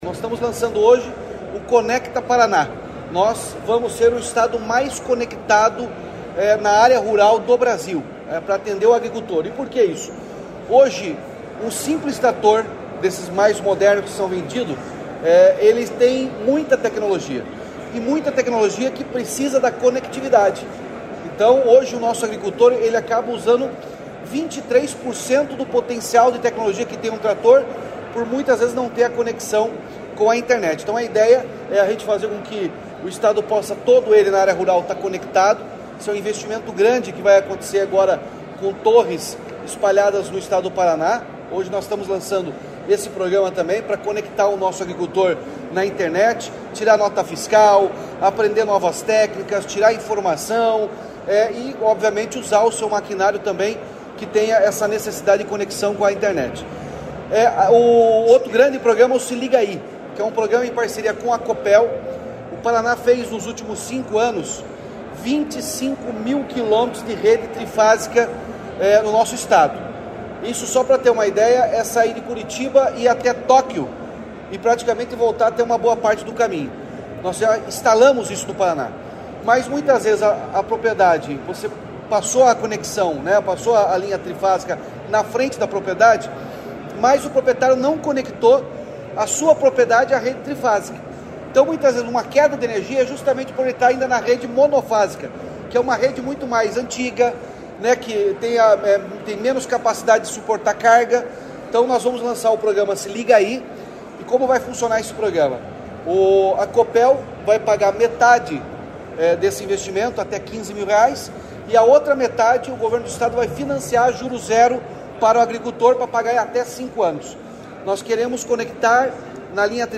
Sonora do governador Ratinho Junior sobre os programas Se Liga Aí Paraná e Paraná Conectado